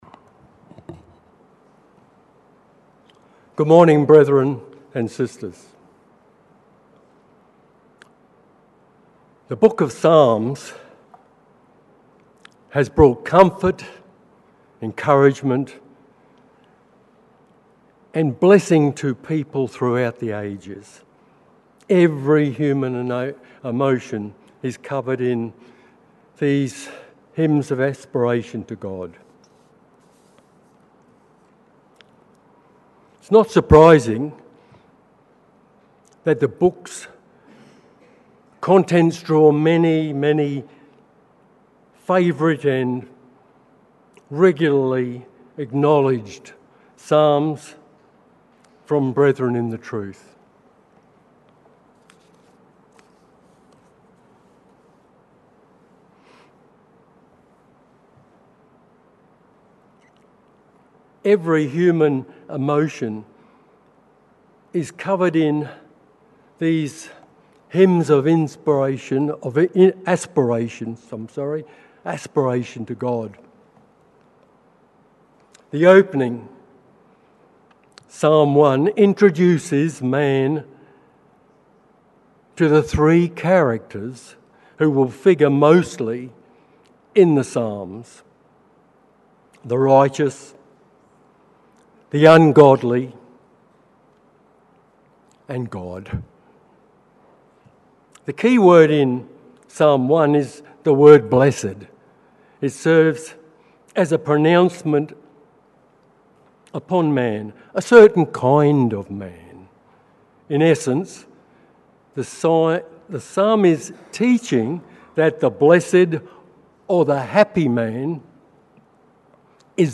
Exhortation - Comfort from the Psalms - Know Your Bible